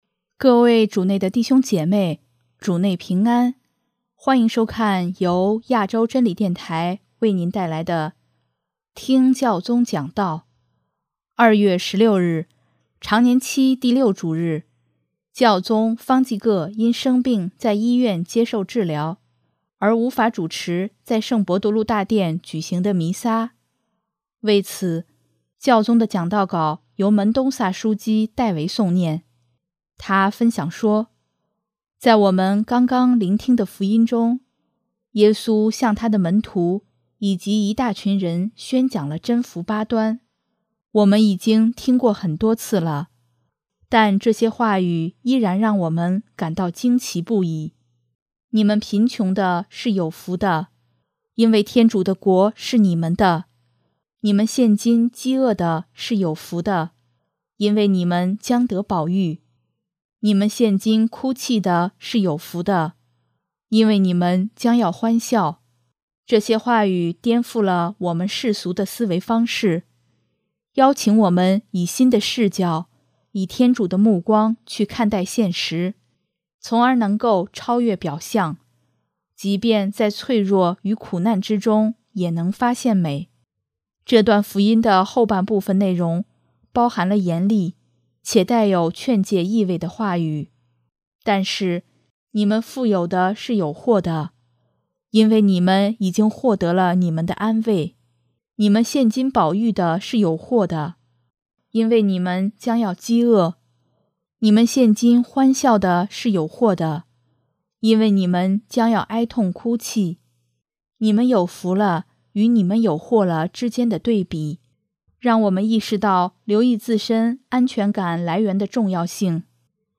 2月16日，常年期第六主日，教宗方济各因生病在医院接受治疗，而无法主持在圣伯多禄大殿举行的弥撒。为此，教宗的讲道稿由门东萨枢机（José Tolentino de Mendonça）代为诵念。